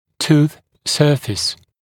[tuːθ ‘sɜːfɪs][ту:с ‘сё:фис]поверхность зуба